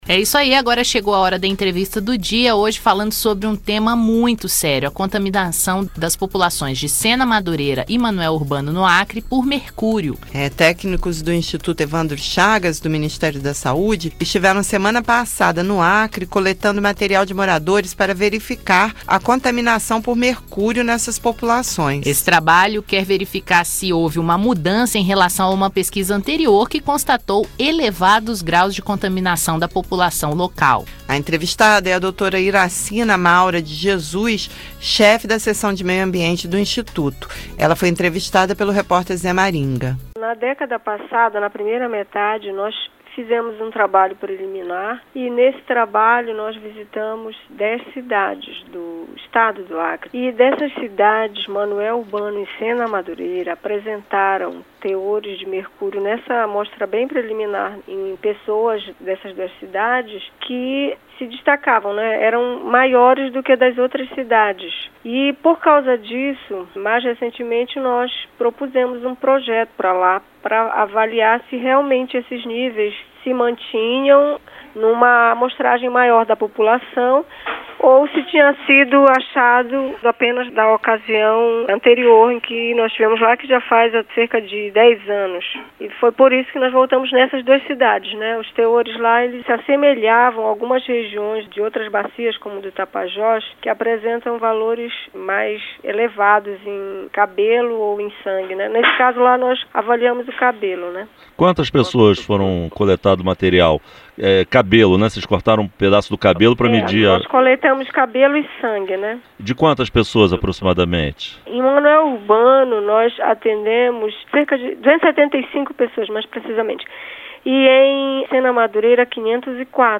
Entrevistas regionais, notícias e informações sobre o Senado Federal